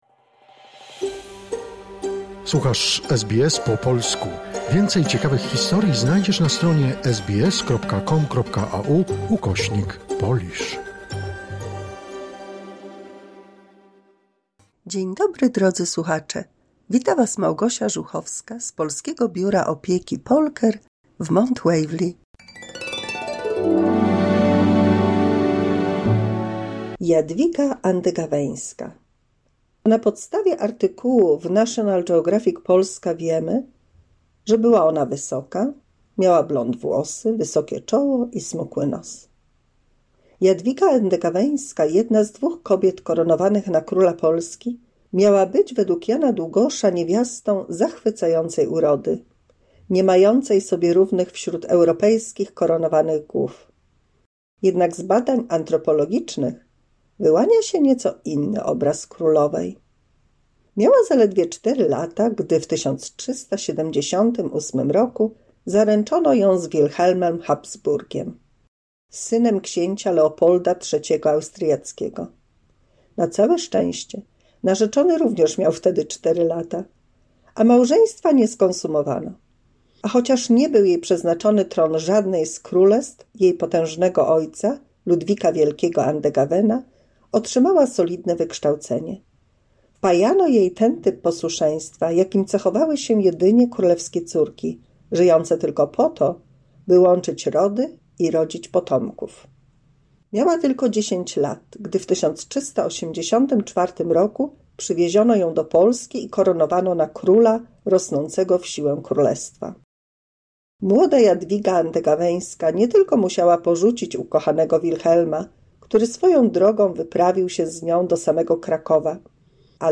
W 219 mini słuchowisku dla polskich seniorów mowa o postaci Jadwigi Andegaweńskiej Królowej Polski.